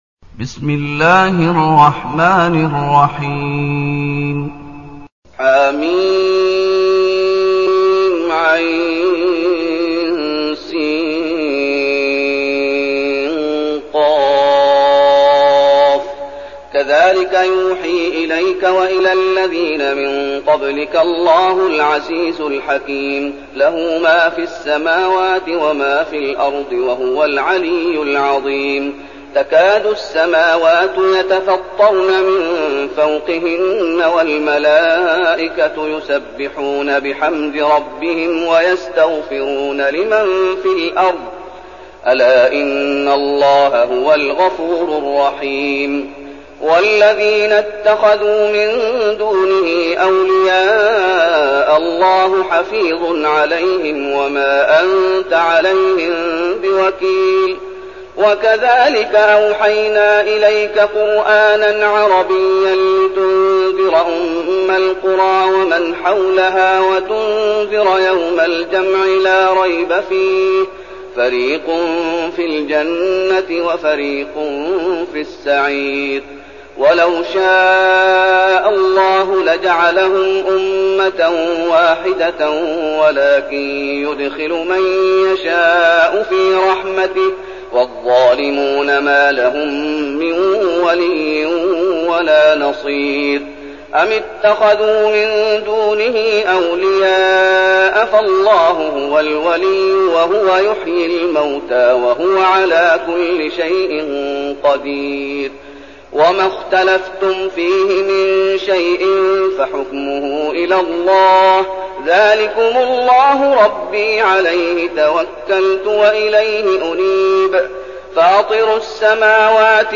تلاوة سورة الشورى
تاريخ النشر ١ محرم ١٤١٠ المكان: المسجد النبوي الشيخ: فضيلة الشيخ محمد أيوب فضيلة الشيخ محمد أيوب سورة الشورى